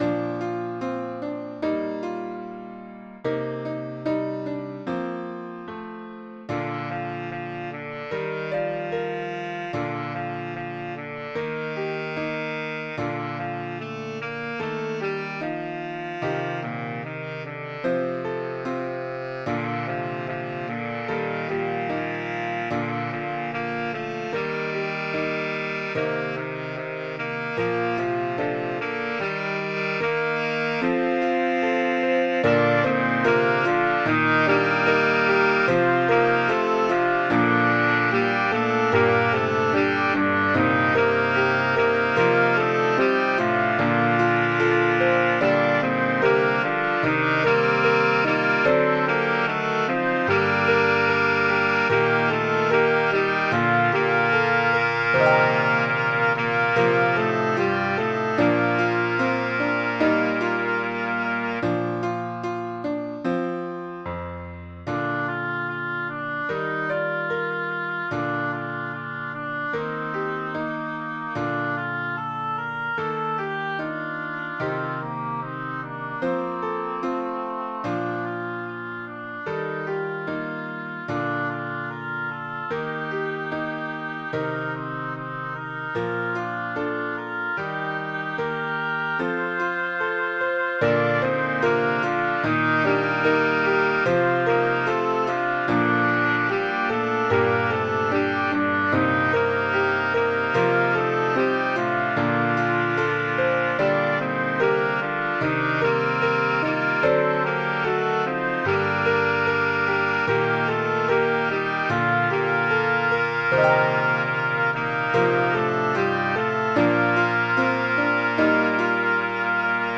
Voicing/Instrumentation: SATB We also have other 13 arrangements of " I Will Walk with Jesus ".